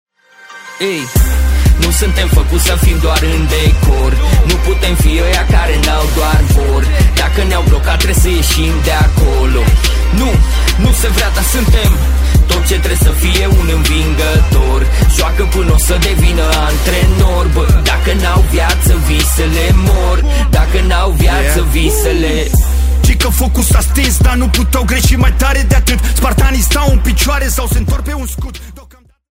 Categorie: Hip-Hop